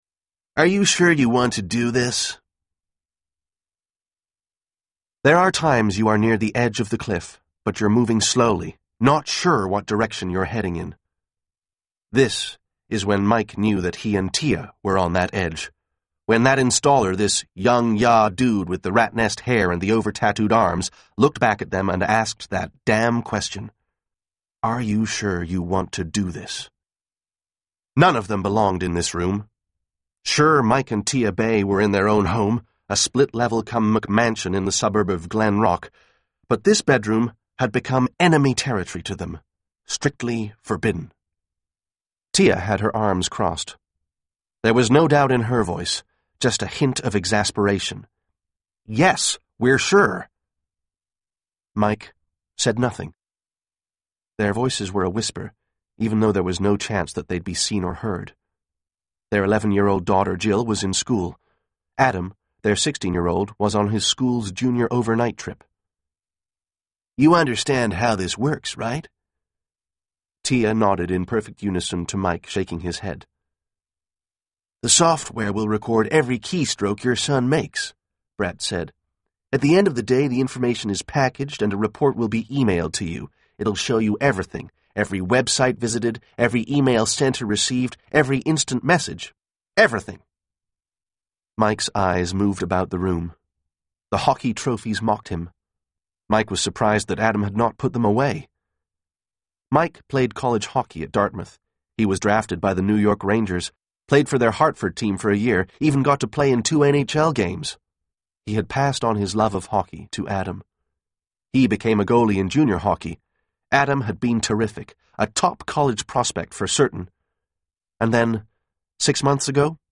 Hold Tight Audio Book Sample